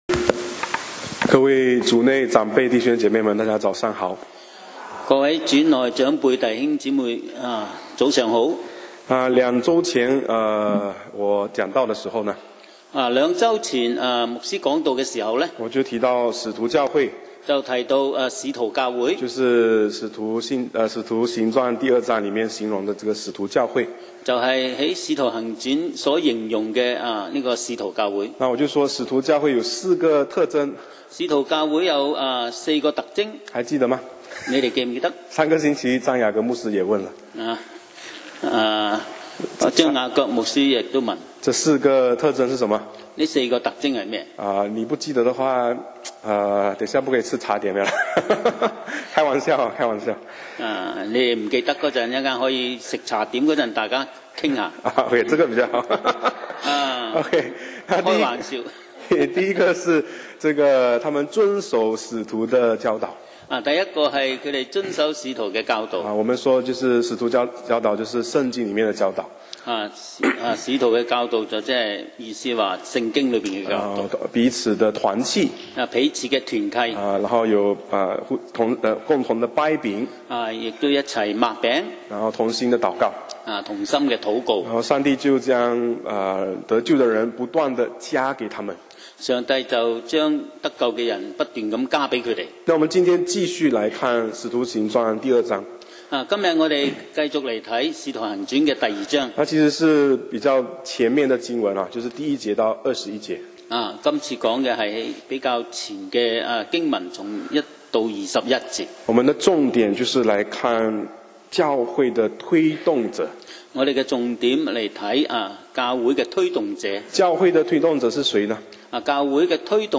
講道 Sermon 題目 Topic：靈恩教會？